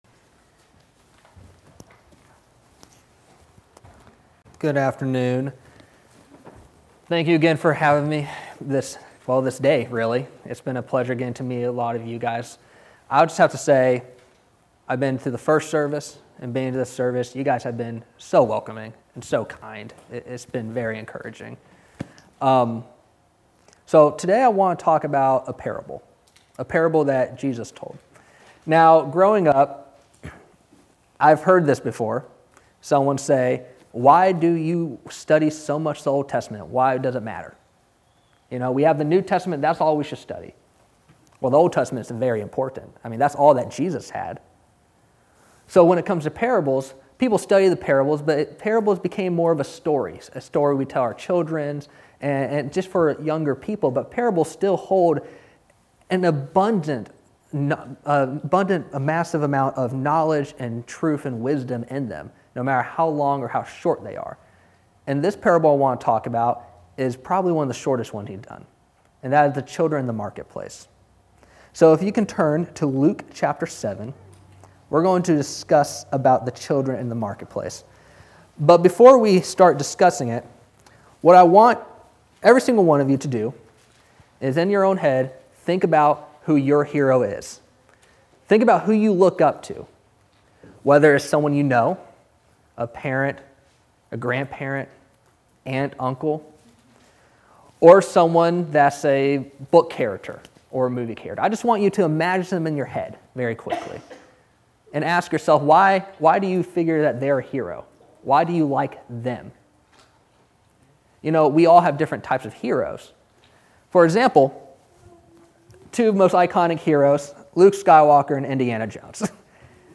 Sun PM Sermon – Isaac – 08.03.25